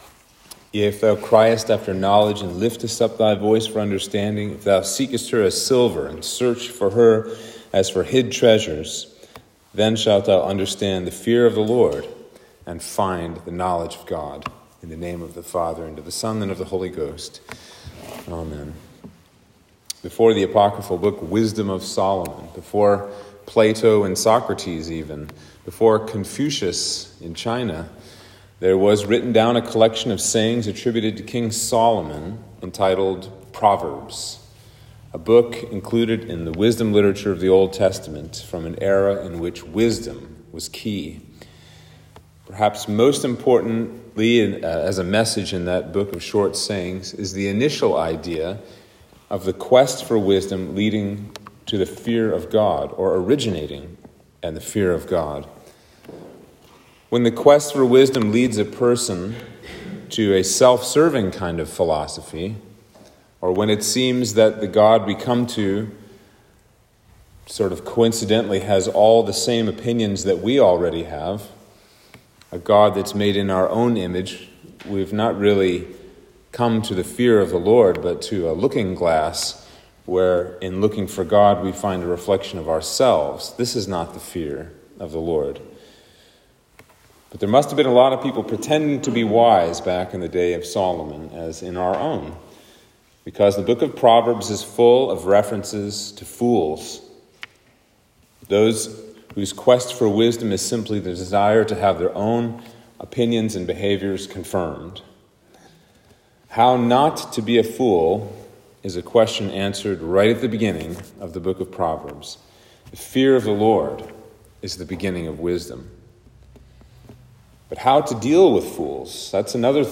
Sermon for Trinity 18